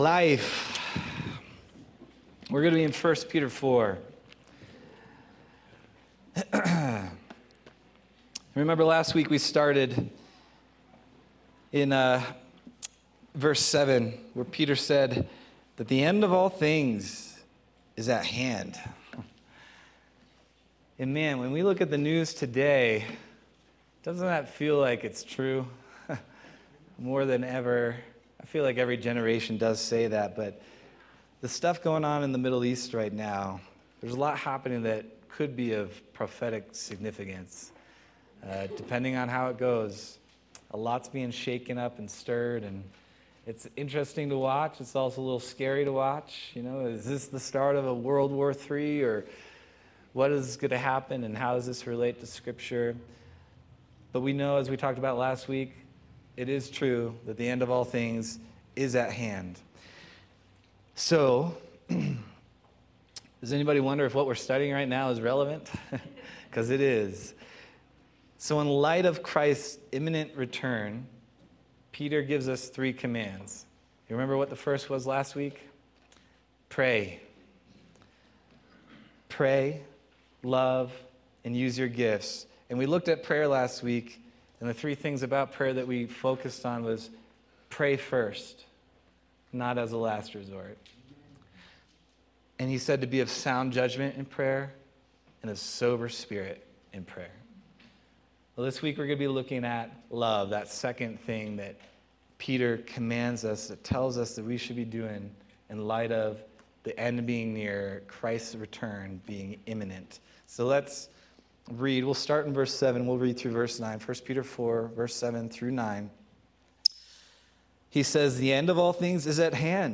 June 22nd, 2025 Sermon